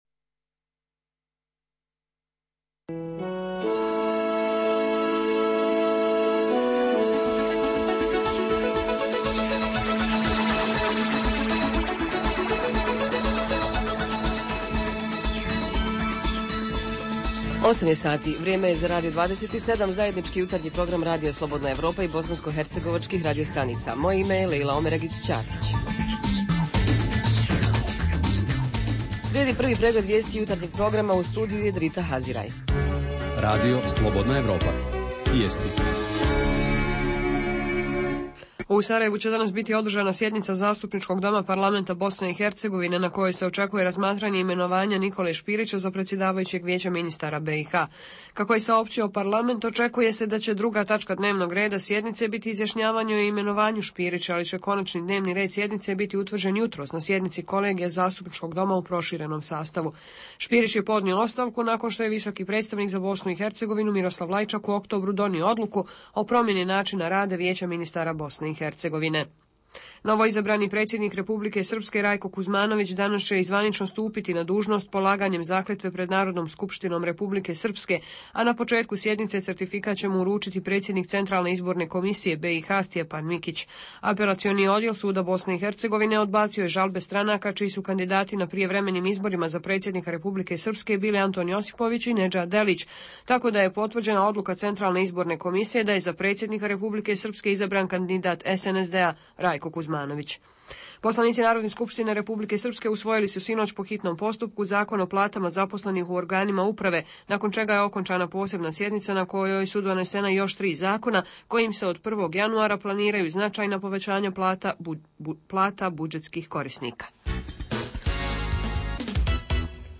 Tema jutarnjeg programa je kako rade zimske službe, jesu li položile ispit, ima li nekih novina u održavanju puteva prohodnim i kako su se pokazala klasična sredstva. Pored mišljenja nadležnih komunalaca, čućemo i vozače, te građane u Brčkom, na primjer, gdje je interesantnije da li se čisti snijeg sa ulica, nego kakve su ceste.
Redovni sadržaji jutarnjeg programa za BiH su i vijesti i muzika.